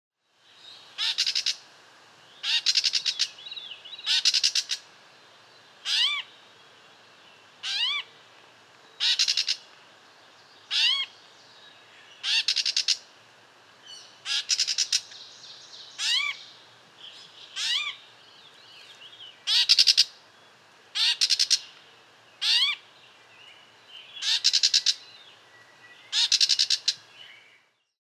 Их песни состоят из случайных, но повторяющихся нот, включающих имитации других видов и неожиданные звуки.
Звук птицы кошачий пересмешник gray catbird